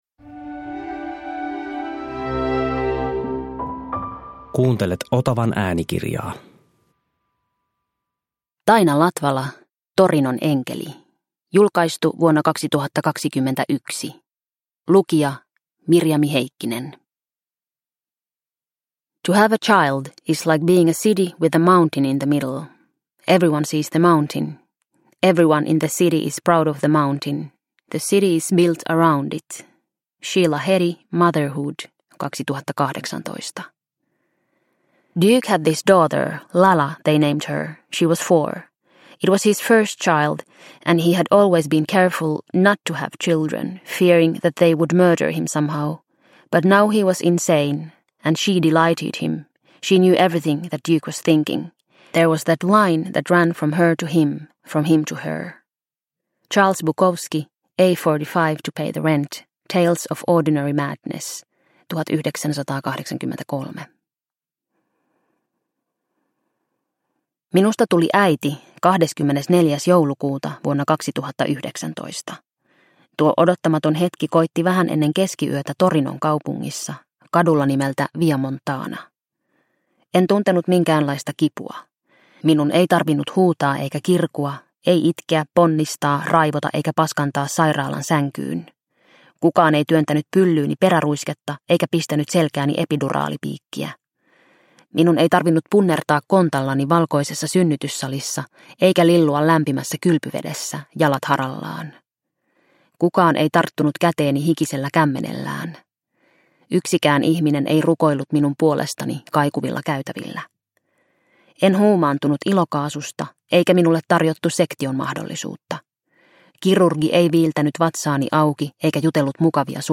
Torinon enkeli – Ljudbok – Laddas ner